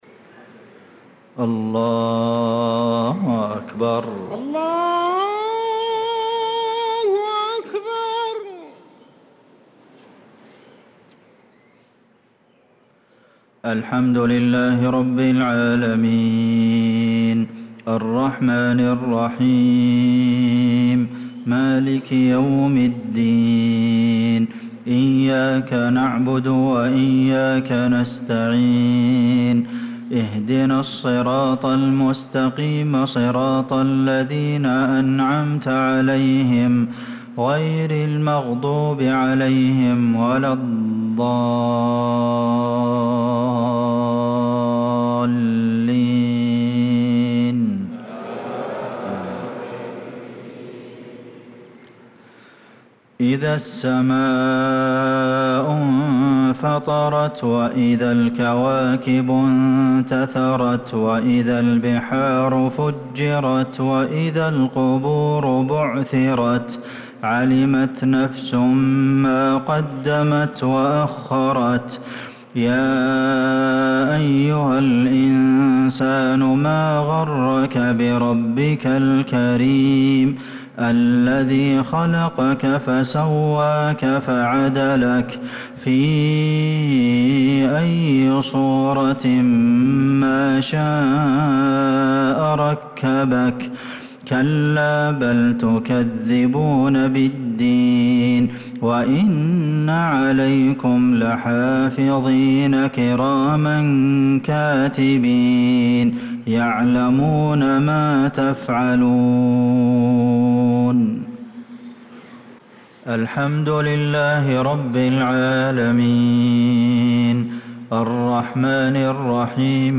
صلاة المغرب للقارئ عبدالمحسن القاسم 9 ذو الحجة 1445 هـ
تِلَاوَات الْحَرَمَيْن .